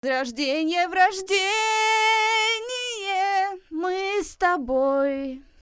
Друг, помоги! Стал грязнится звук вокала
цепь такая Rode nt1000 ( Rode NT1, AKG P120) любой из микрофоном эффект дает такой же.
Кто с таким сталкивался? по уровню все отлично пишу на уровне -8-10 db